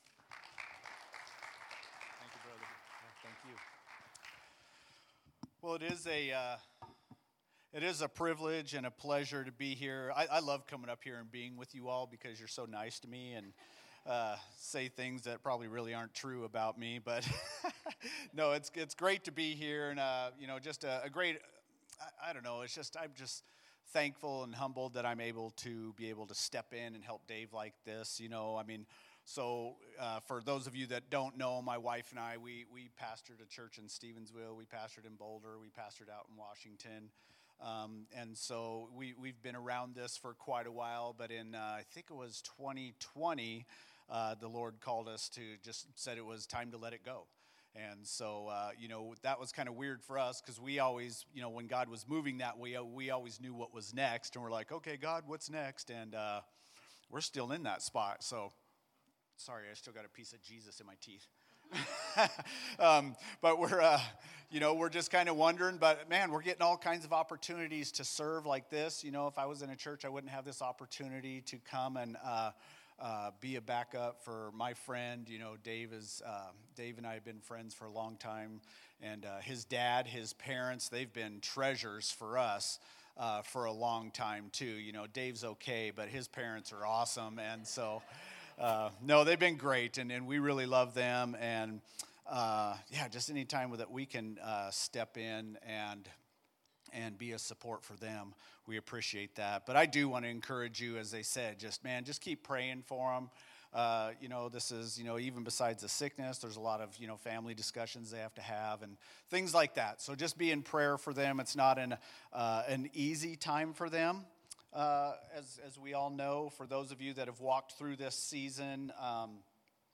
Guest Speaker
Passage: John 15:1-11 Service Type: Sunday Morning « “Doing Church Together